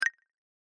escribir.mp3